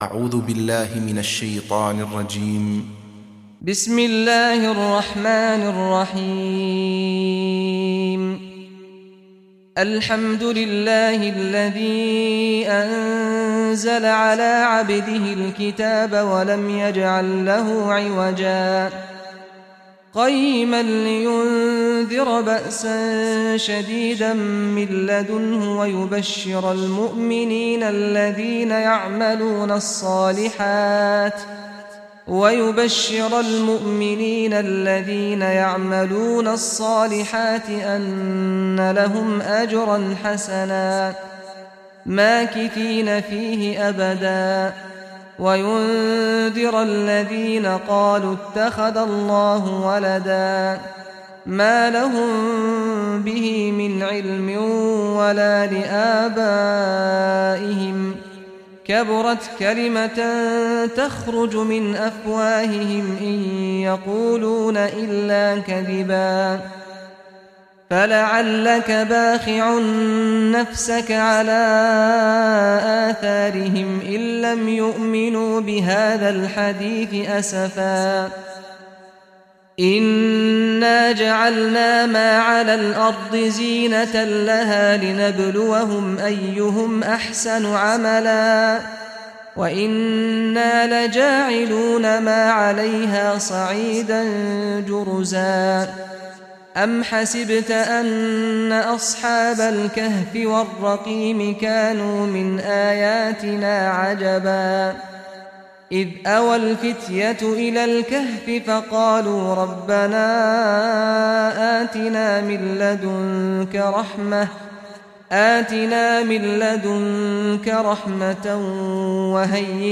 Surah Kahf Recitation by Sheikh Saad al Ghamdi
Surah Kahf, listen or play online mp3 tilawat / recitation in Arabic in the beautiful voice of Imam Sheikh Saad al Ghamdi.